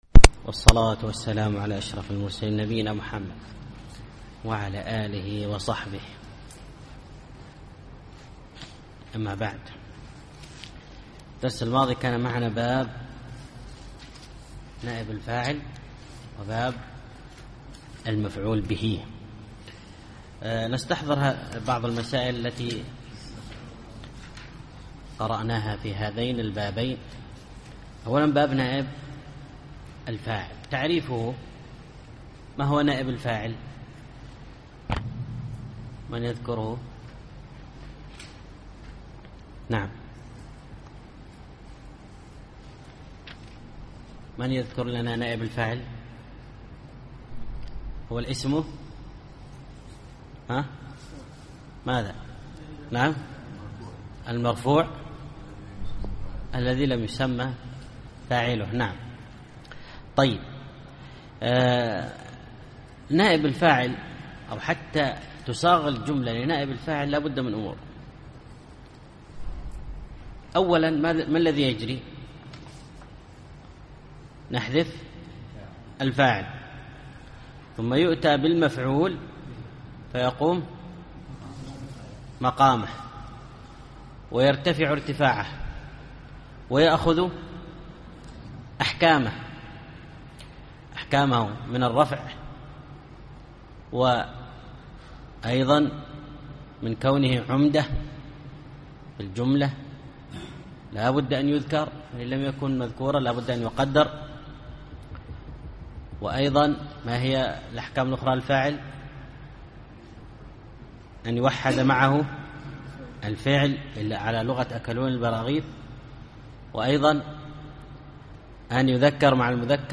الدرس العاشر الأبيات 124-128